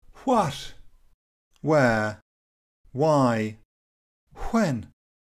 Irish7.mp3